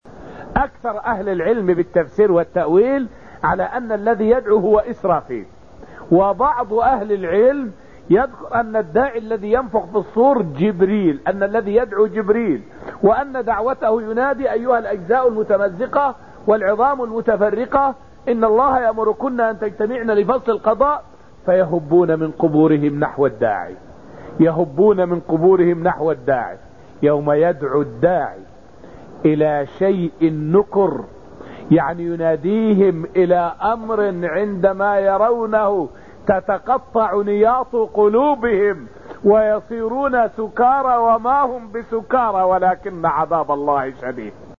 فائدة من الدرس الخامس من دروس تفسير سورة القمر والتي ألقيت في المسجد النبوي الشريف حول أن السعادة في هدى اتباع الله وسنة نبيه.